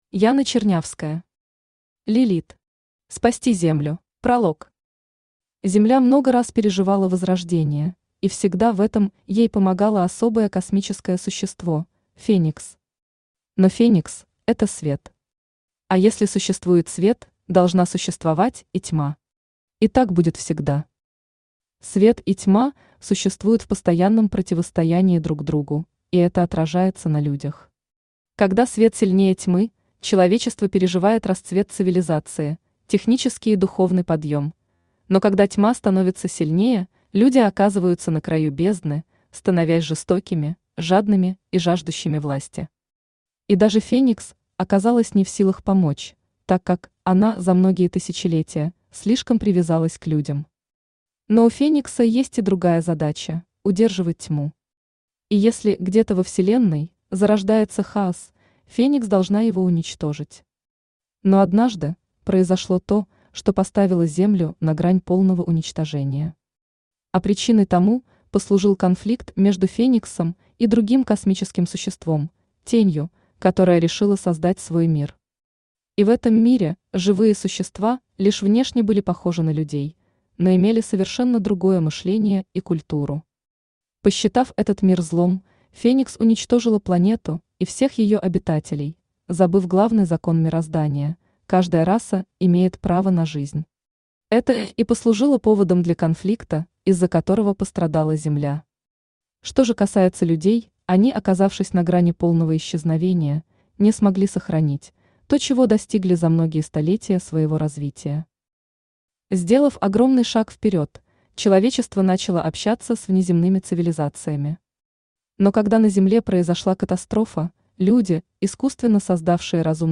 Aудиокнига Лилит. Спасти Землю Автор Яна Чернявская Читает аудиокнигу Авточтец ЛитРес. Прослушать и бесплатно скачать фрагмент аудиокниги